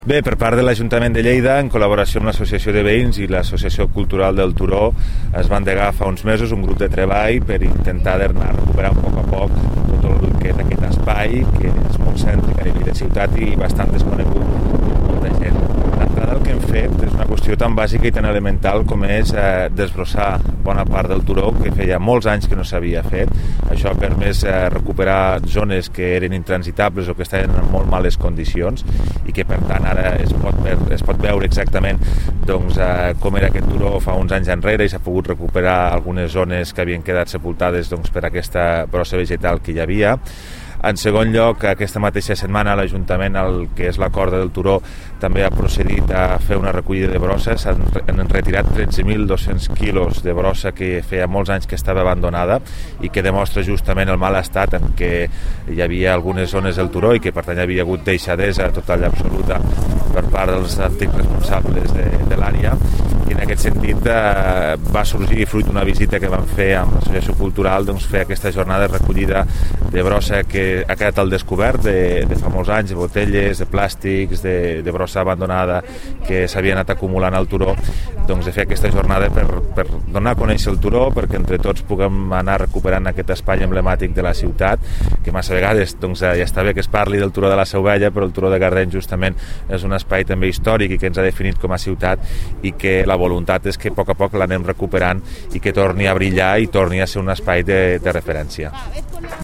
tall-de-veu-toni-postius